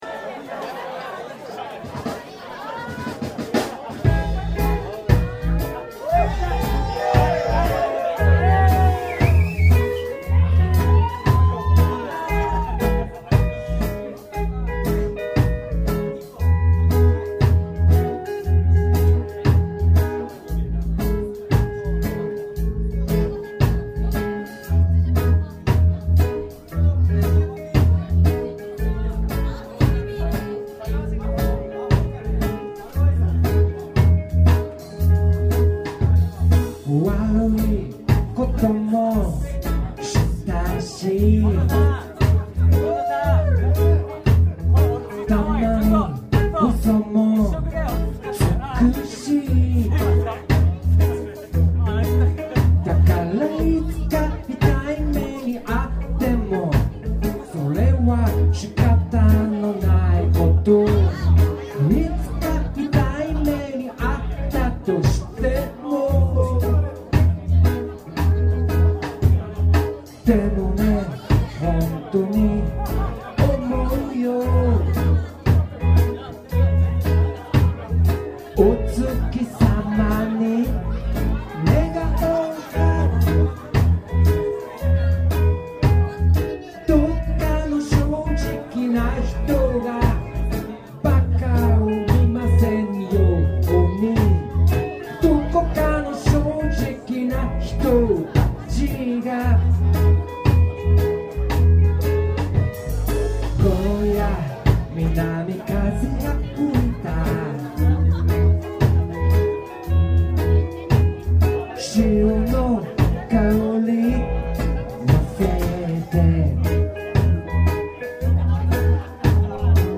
2008年7月5日【会津】BARD LAND
たっぷり演奏しました。